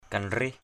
/ka-nrih/ (d.) nan (tre, sậy). krih kanrih k{H kn{H vót nan.